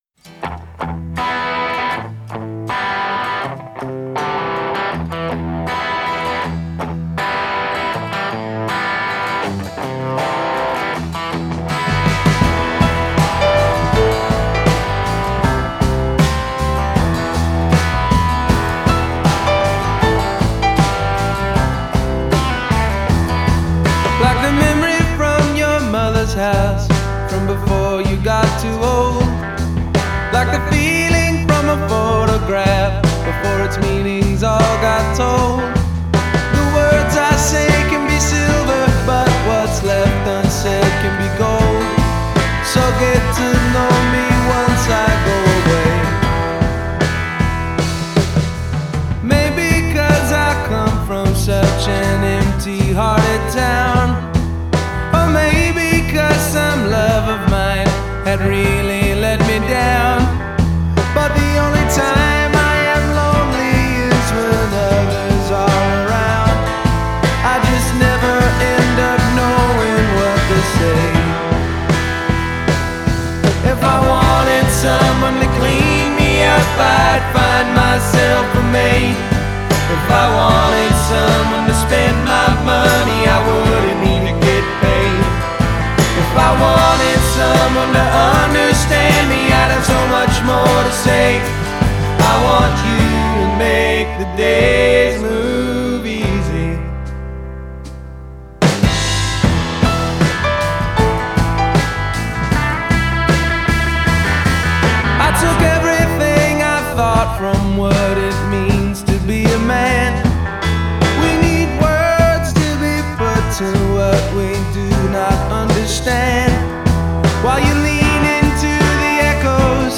Mood: Driving on a Sunny Day, Sitting by a Fire at Night